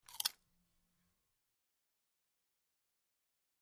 Bite Into Many Potato Chips, X7